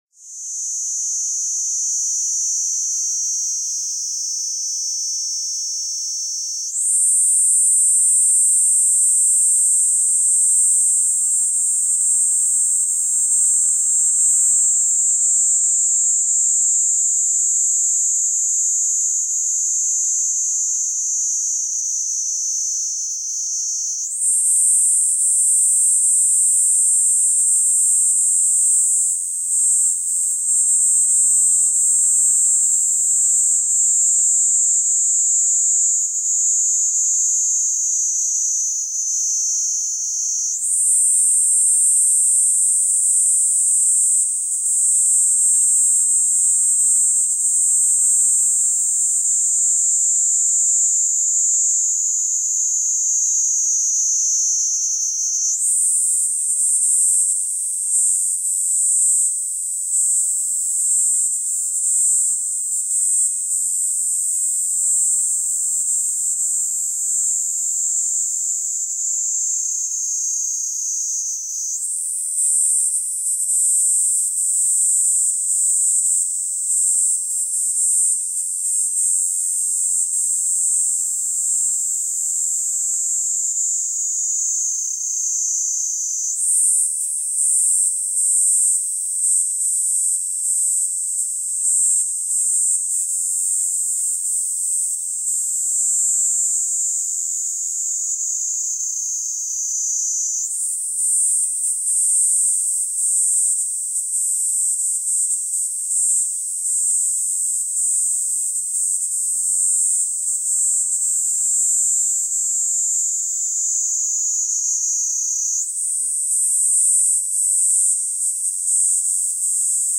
ニイニイゼミ
チーーーーー……